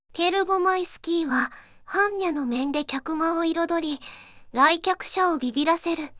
voicevox-voice-corpus